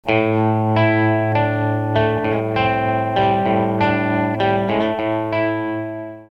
TREMOLO
Le trémolo joue sur le volume faisant varier l'amplitude du son à l'attaque de la note. A faible dose, il fait vibrer lentement le son, tel que sur le sample :
tremolo.mp3